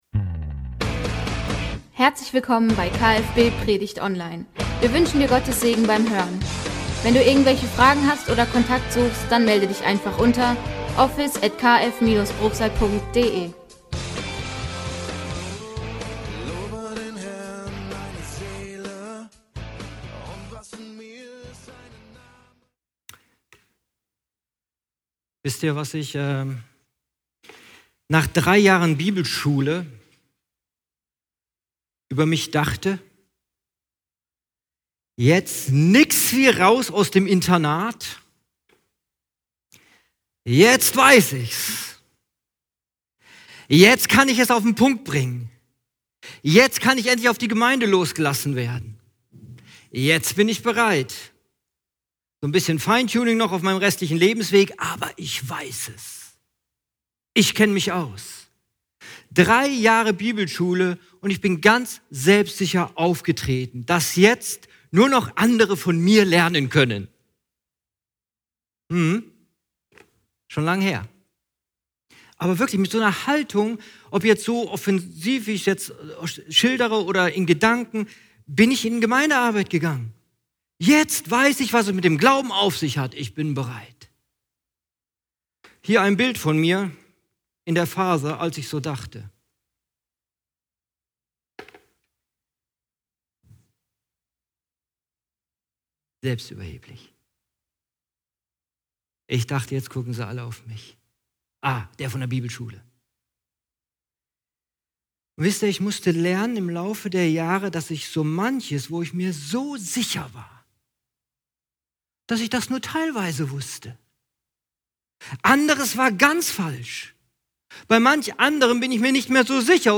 Gottesdienst: Ganz schön selbstsicher ?!